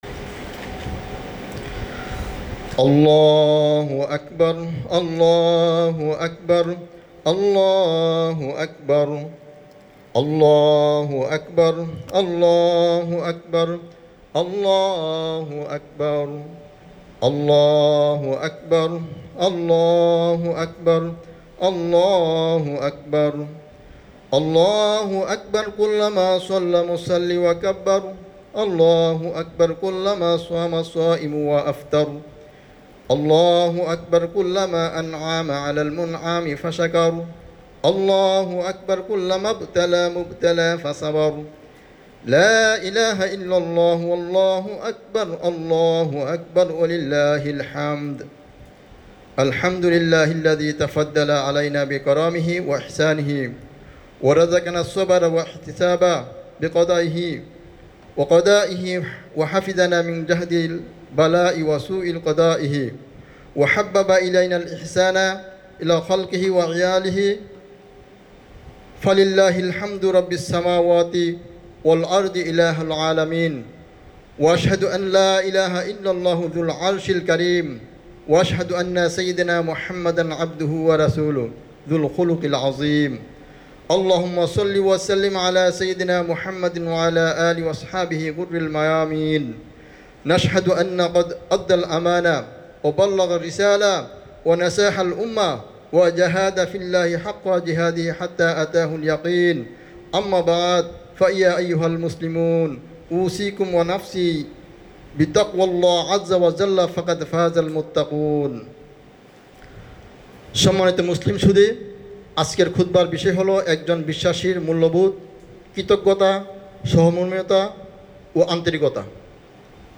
Eidul Fitri Sermon – 31 March 2025 / 1 Syawal 1446H – একজন বিশ্বাসীর মূল্যবোধঃ কৃতজ্ঞতা, সহমর্মিতা ও আন্তরিকতা
31Mar25-Bengali-Eid-Khutbah_2.mp3